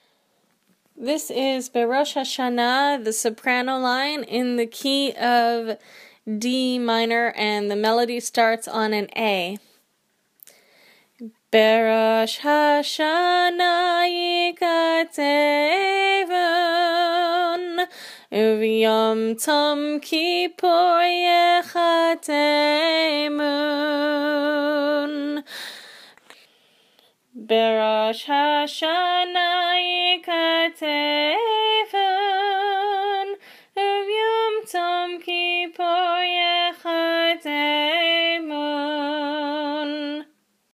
Brosh Hashanah Soprano